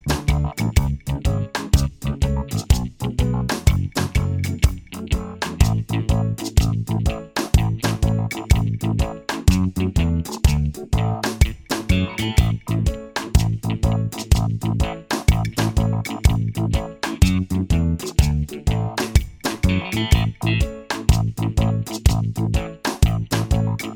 Minus Rhythm Guitar Reggae 4:26 Buy £1.50